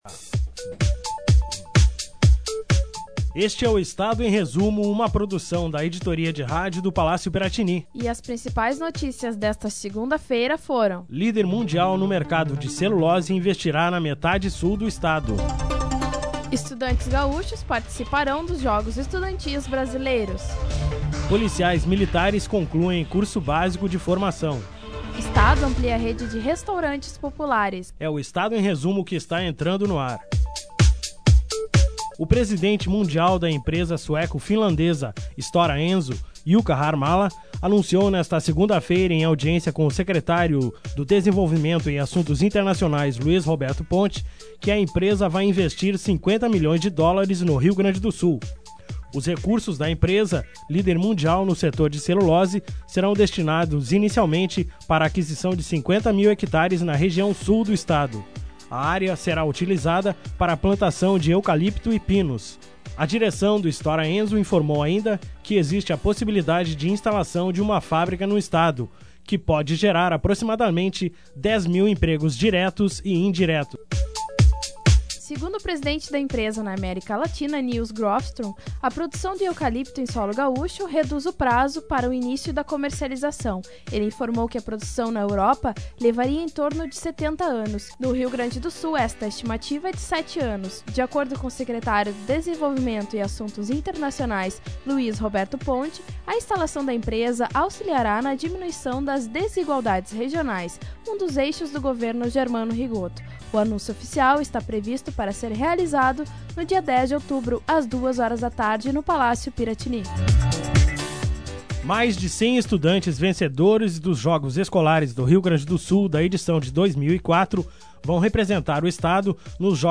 2005-09-26-resumo-de-noticias.mp3